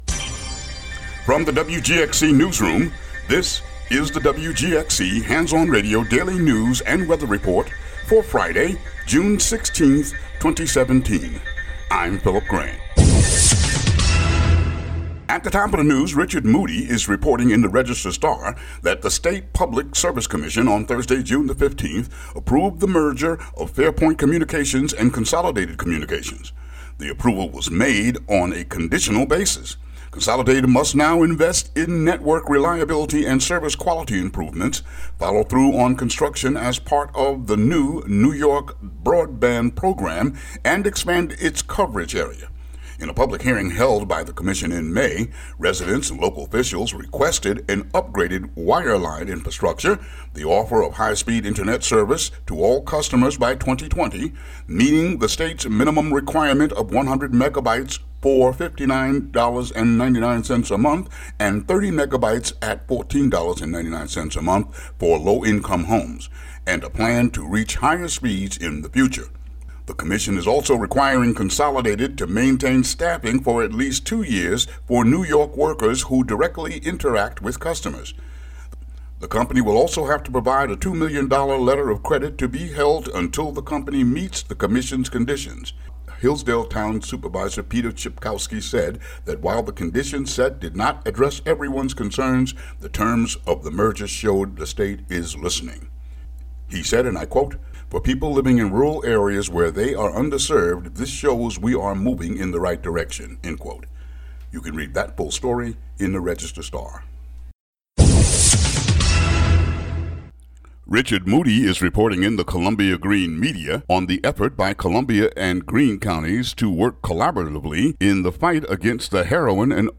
WGXC daily headlines for June 16, 2017.